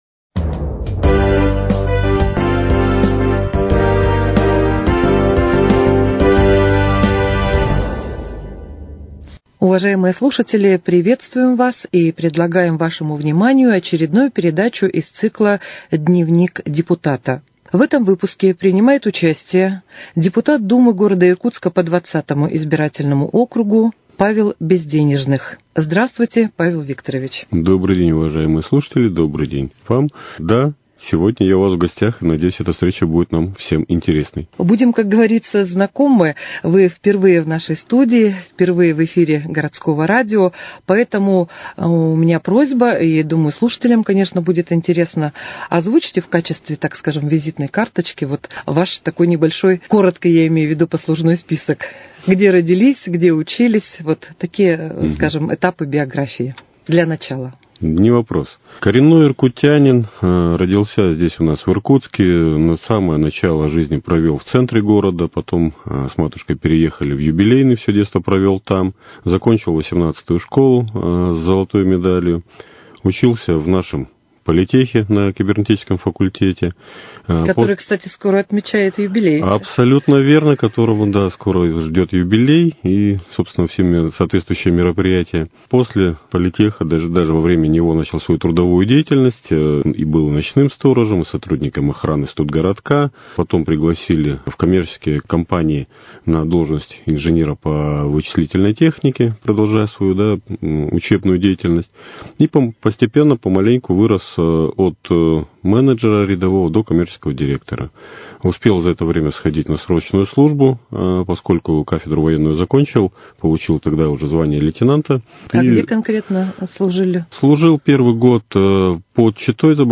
В передаче принимает участие депутат Думы г.Иркутска по округу № 20 Павел Безденежных.